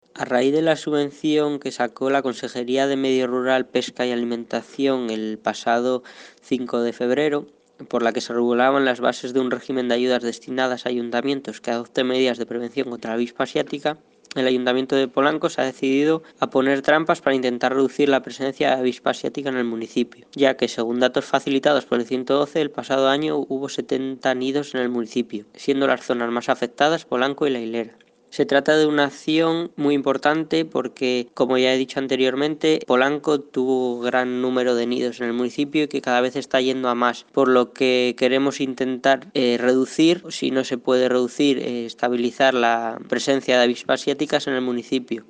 Concejal-Medio-Ambiente-sobre-campaña-trampeo-avispa-asitica.mp3